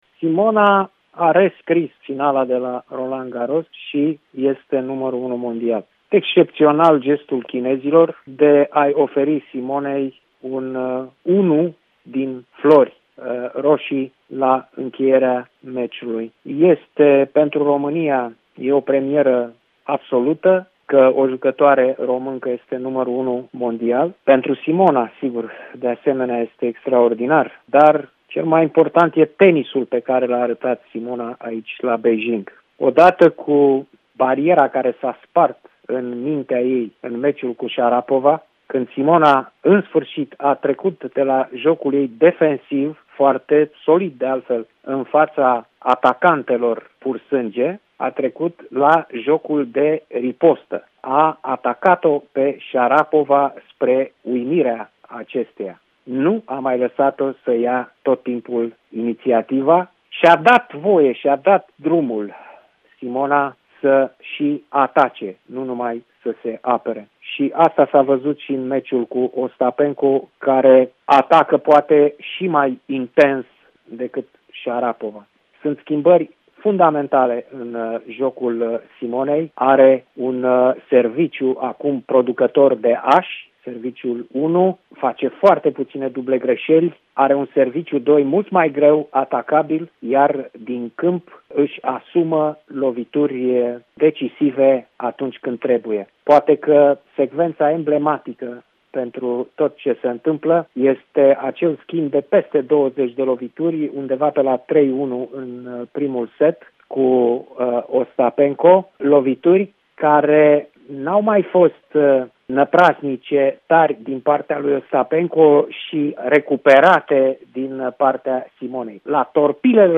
Editorialistul Cristian Tudor Popescu a comentat pentru EUROPA FM trimful Simonei Halep, care – calificată în finala turneului de la Beijing – a urcat pe prima poziție în clasamentul mondial feminin (WTA).